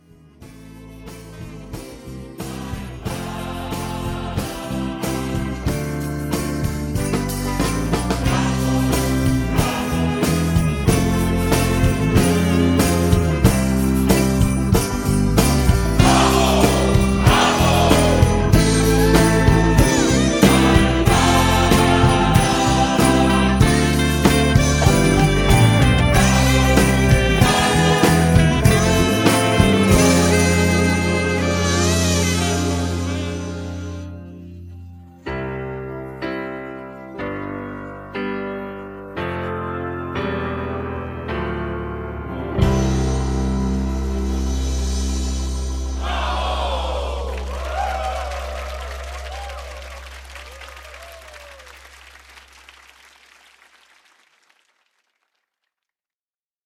음정 -1키 4:23
장르 가요 구분 Voice MR